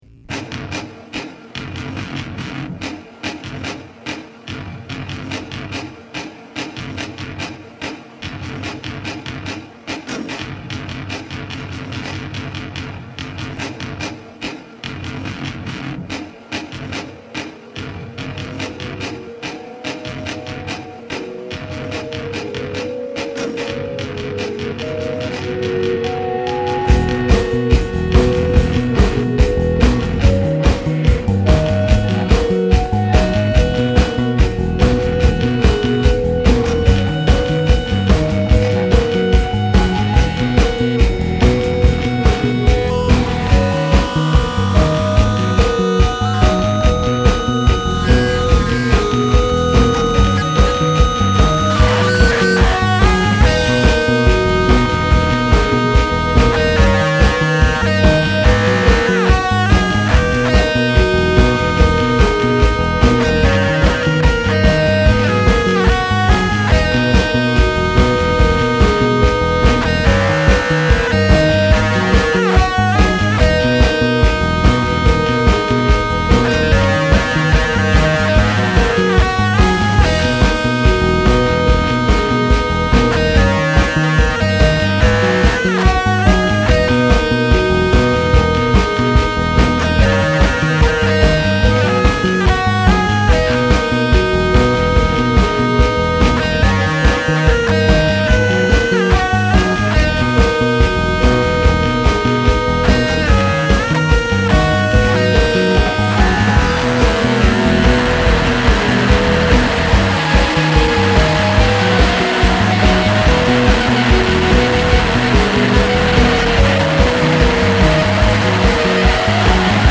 Start with feedback in F#
The playing varies each time.
Its kinda played sloppily.
Followed by noisy stuff in E. Go crazy. x 2
Followed by noise in B. Go crazy.